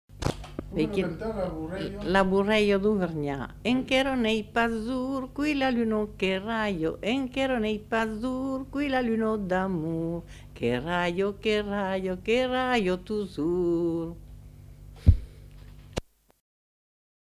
Aire culturelle : Périgord
Lieu : Saint-Rémy-de-Gurson
Genre : chant
Effectif : 1
Type de voix : voix de femme
Production du son : chanté
Danse : bourrée